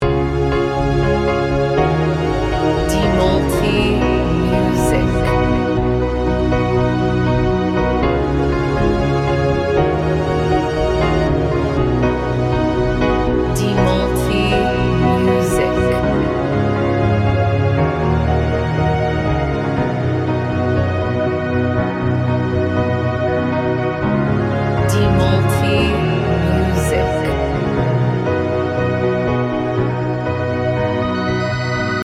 Modern Piano Instrumental